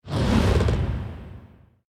archer_skill_chargeshot_01_intro_a.ogg